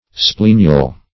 Splenial \Sple"ni*al\, a. [L. splenium a plaster, a patch, Gr.
splenial.mp3